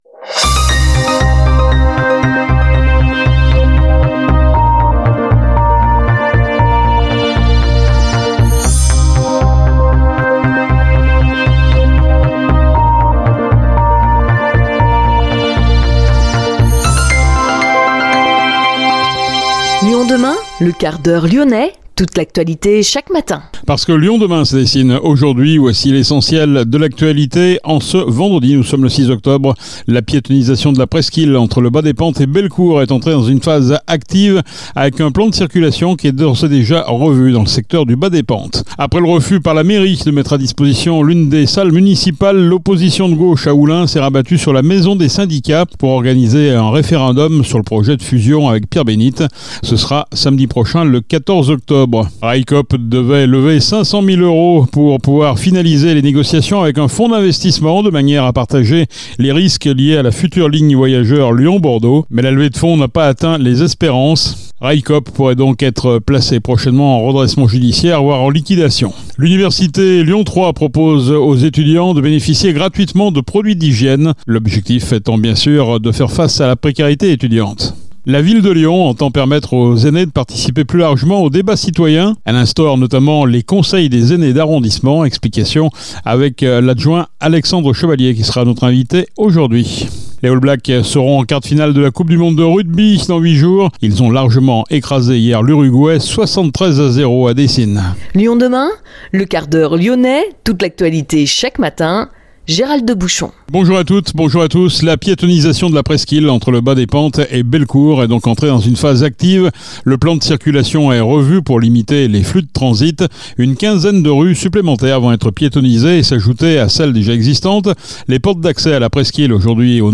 Alexandre Chevalier, adjoint à la qualité de vie des aînés . est notre invité